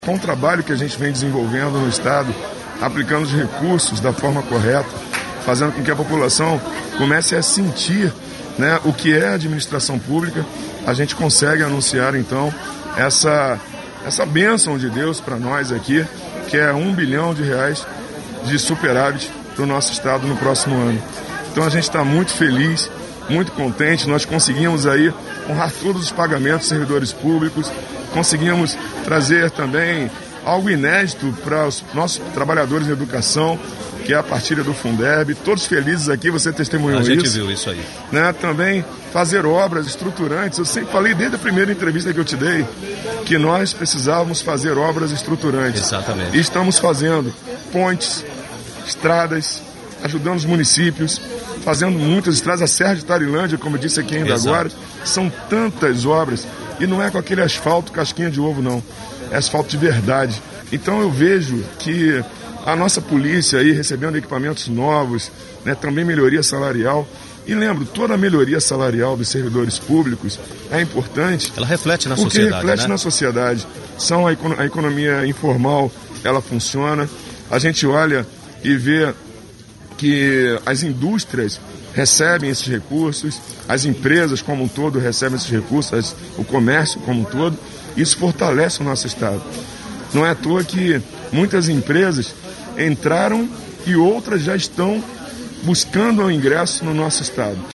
Entrevista-Governador-Marcos-Rocha-01-.mp3